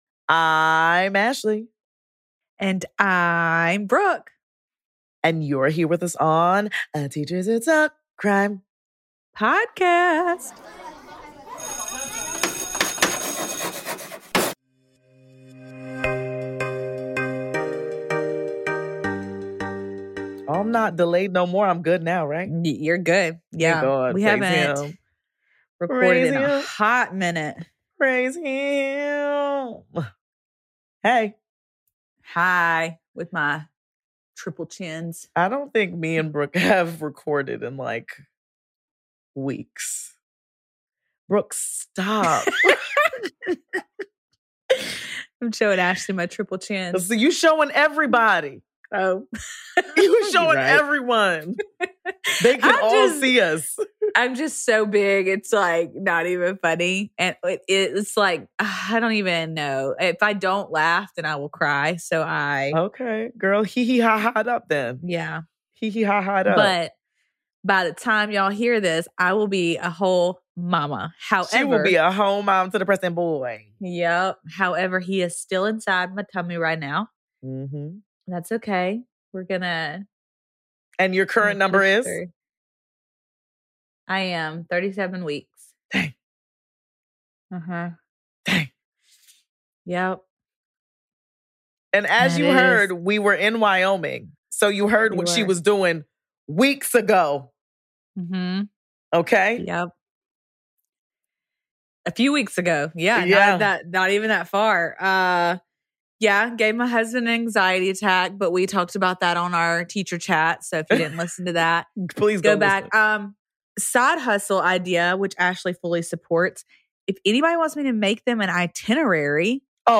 Today’s episode begins at 16:50, so if our banter and chatting isn’t your thing and you want to get right into it we totally understand.